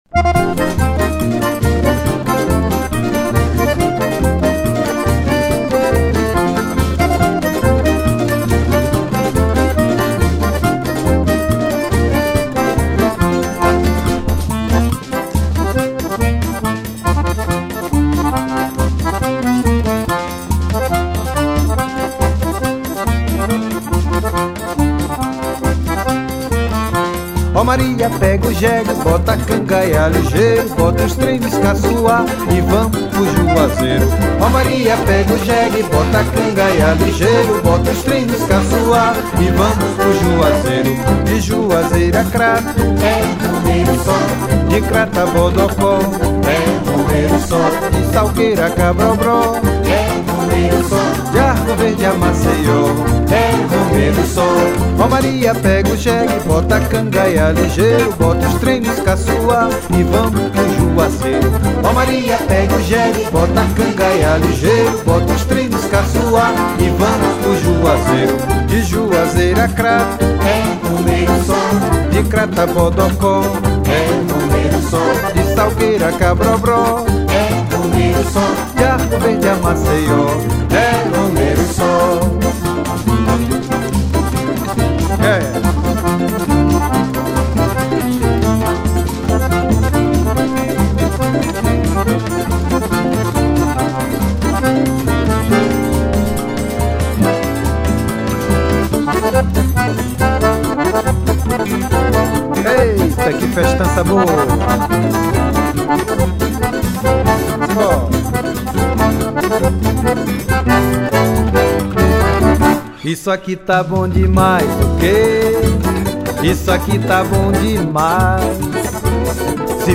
1444   03:19:00   Faixa:     Forró